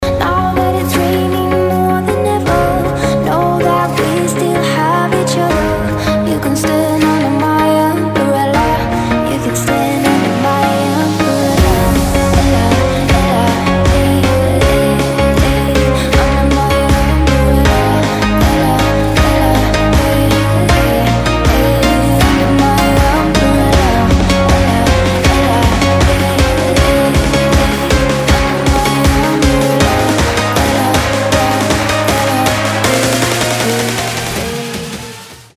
• Качество: 320, Stereo
dance
Electronic
красивый женский вокал